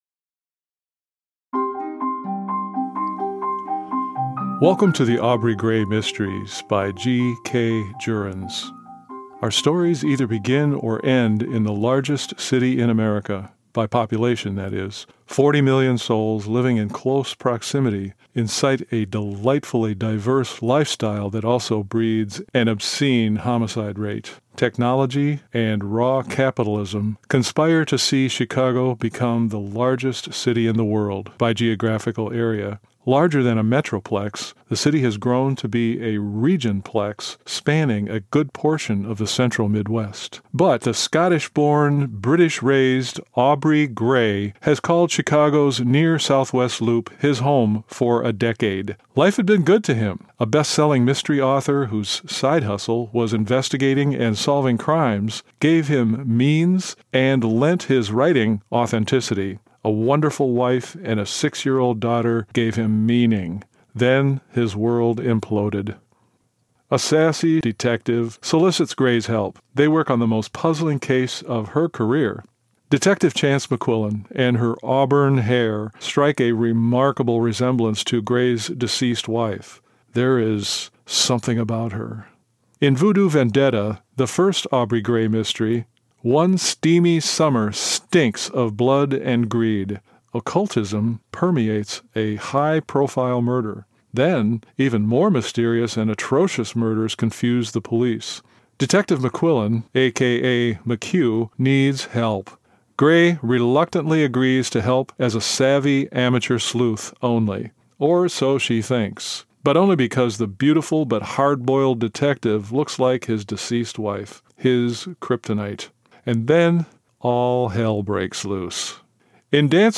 By the way, I narrated and produced the following 3-minute audio trailer (short marketing description) of my Aubrey Greigh Mystery series to date, just for you. It includes a description of this new book. I also composed the music for this piece.
Trailer-Aubrey-Greigh-Mysteries.mp3